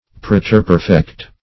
Search Result for " preterperfect" : The Collaborative International Dictionary of English v.0.48: Preterperfect \Pre`ter*per"fect\, a. & n. [Pref. preter- + perfect.]
preterperfect.mp3